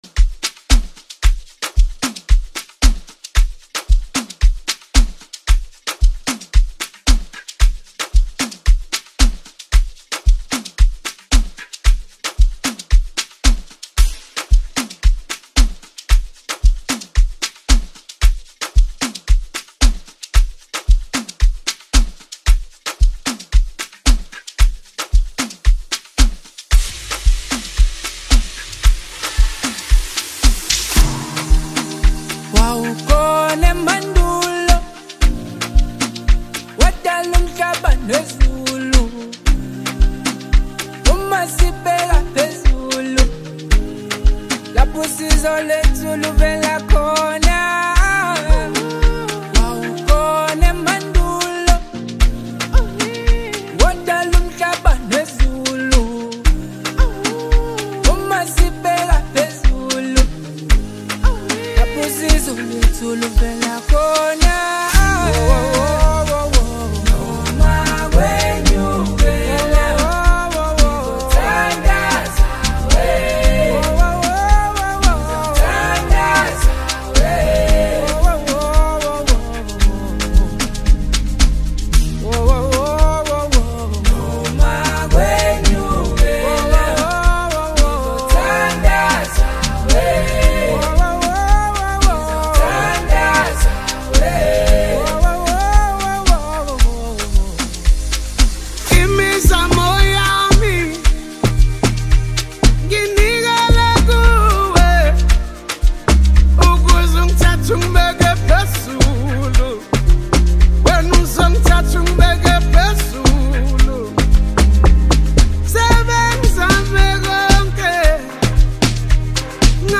soul-stirring praise song
📅 Category: South African Praise Song